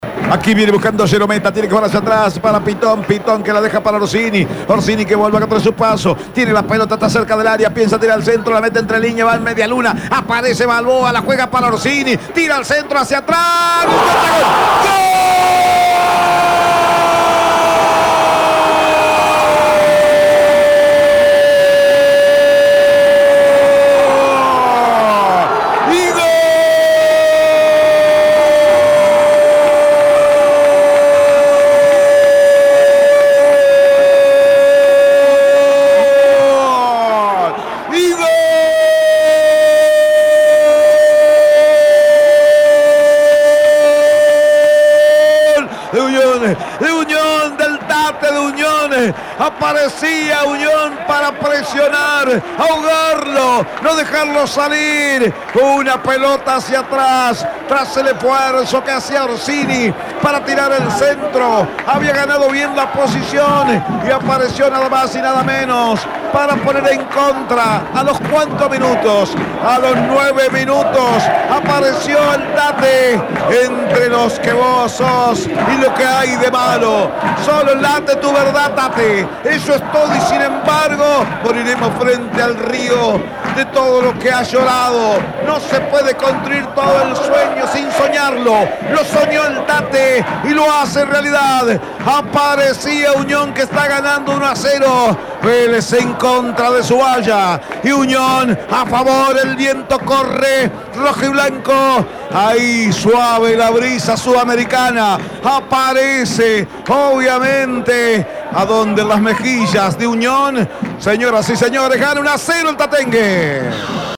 EL GOL DE ELÍAS GÓMEZ EN CONTRA, EN EL RELATO